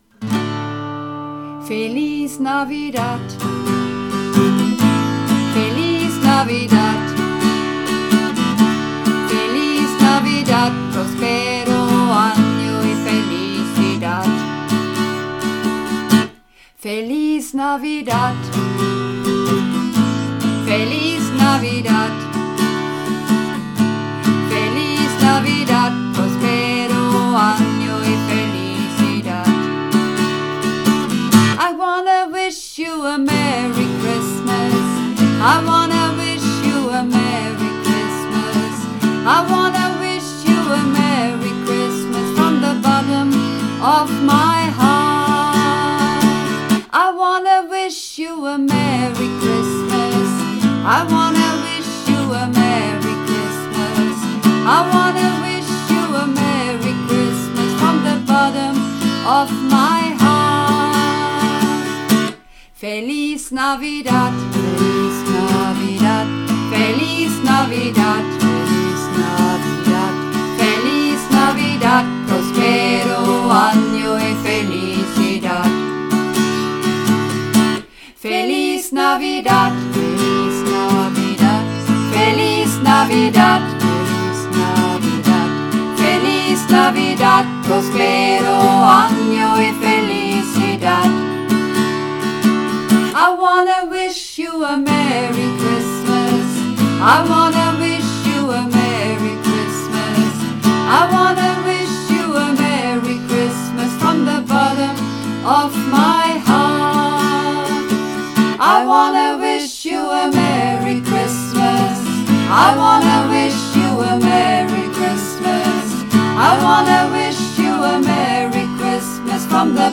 Übungsaufnahmen - Feliz Navidad
Runterladen (Mit rechter Maustaste anklicken, Menübefehl auswählen)   Feliz Navidad (Mehrstimmig)
Feliz_Navidad__4_Mehrstimmig.mp3